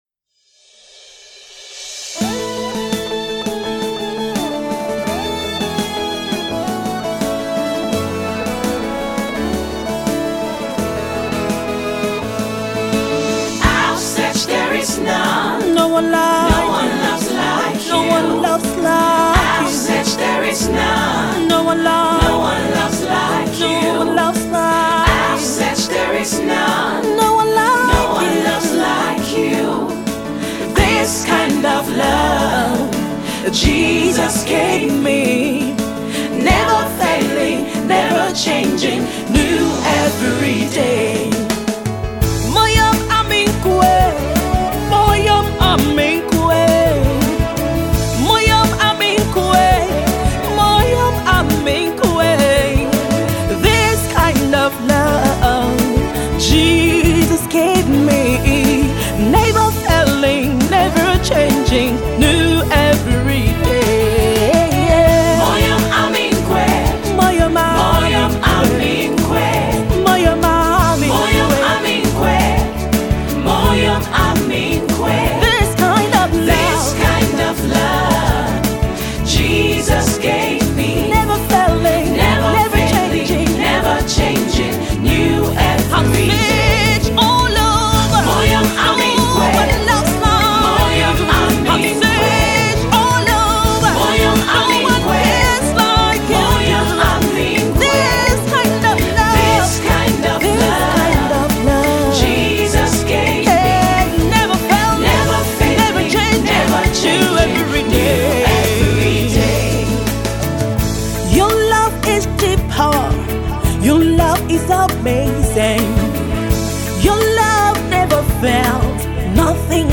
Nigerian British Gospel music minister